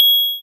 coin-d.ogg